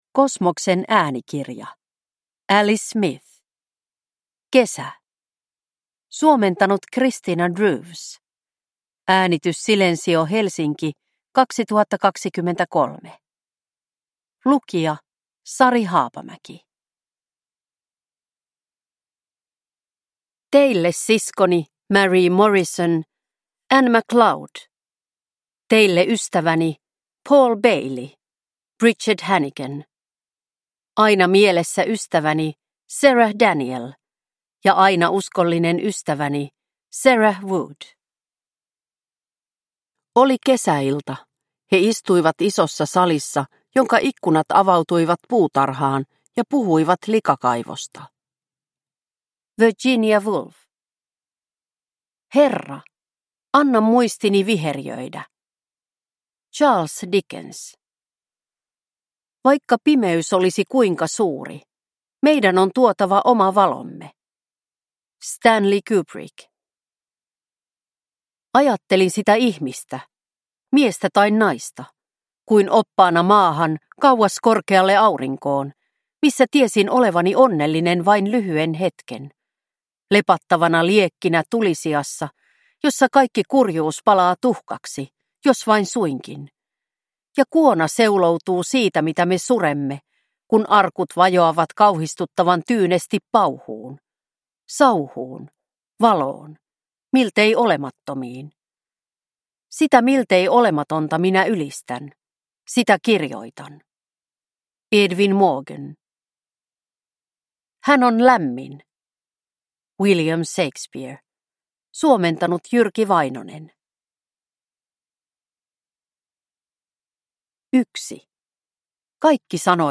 Kesä – Ljudbok – Laddas ner